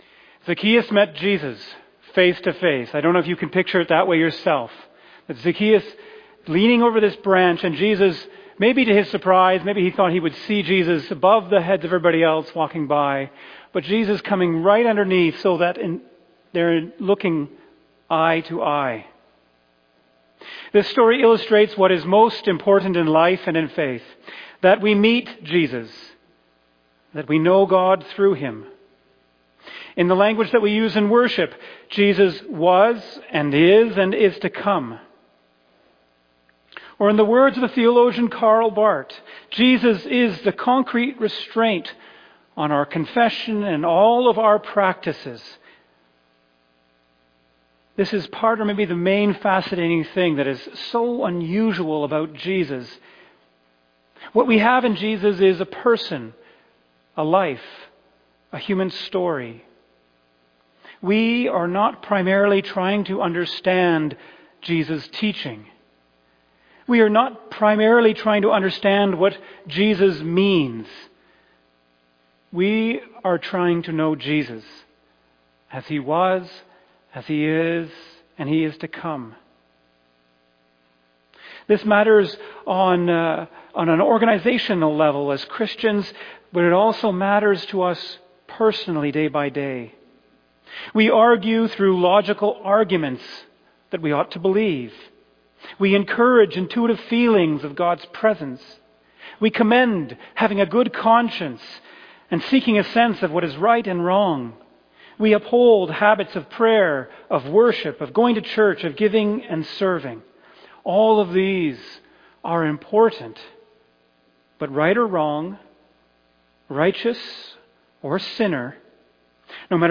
fbcsermon_2025_Apr6.mp3